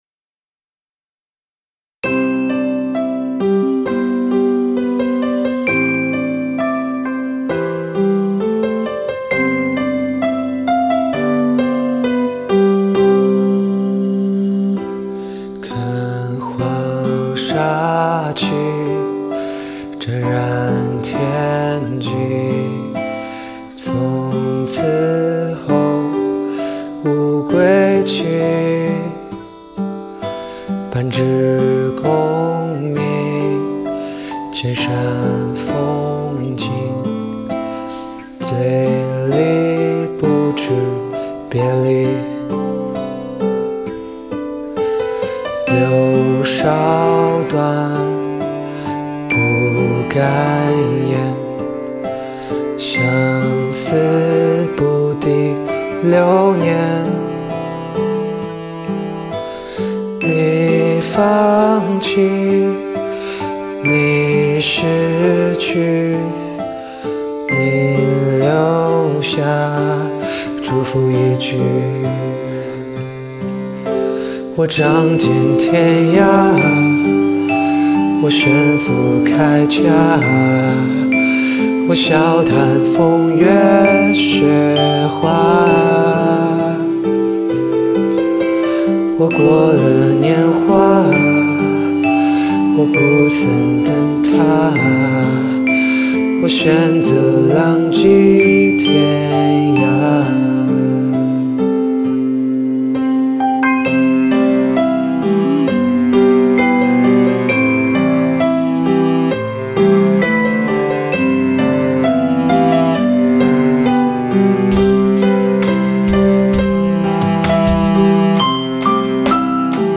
曲风：流行